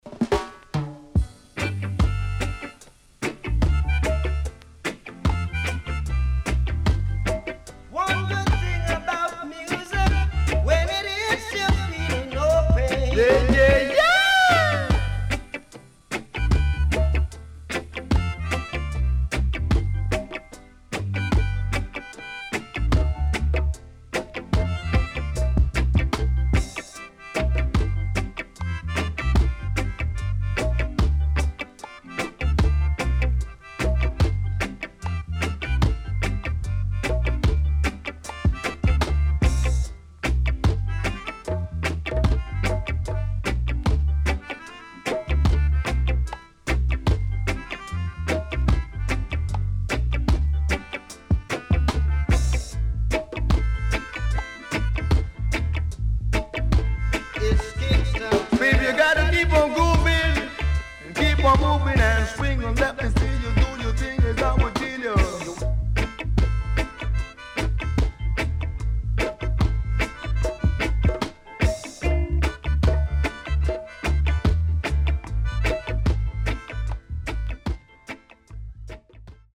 Great DeeJay Cut